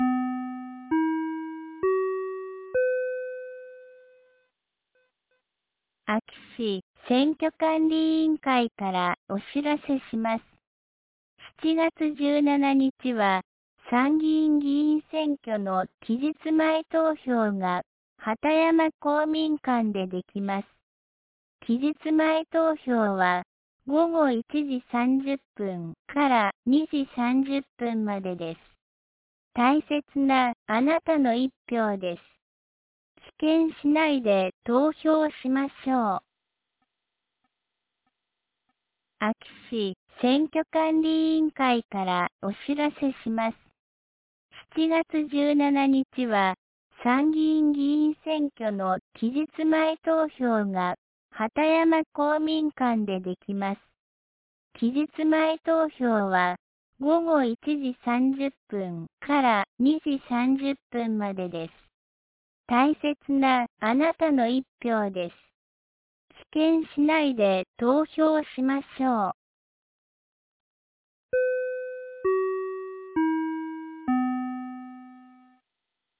2025年07月15日 09時05分に、安芸市より畑山へ放送がありました。